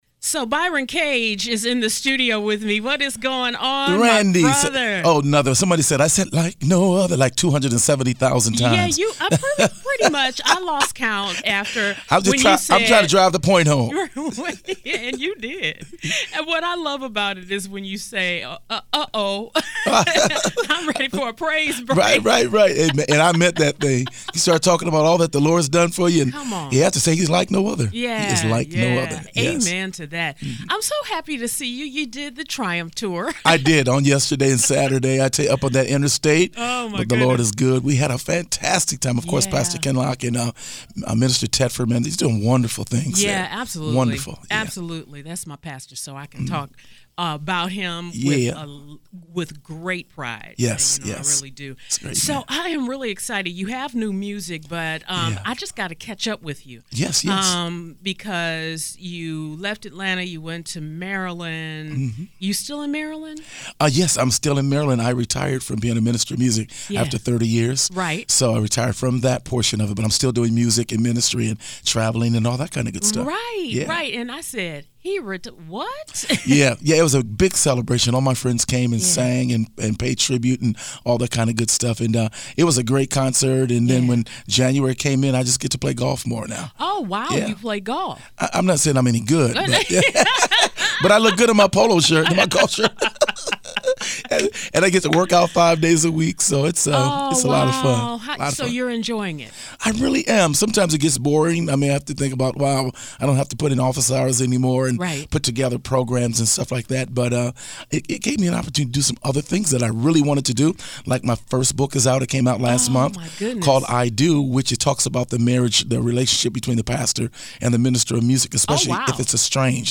It’s always awesome to have my brother Byron Cage in the studio with me! Today, we talked about his new project “Isolation.”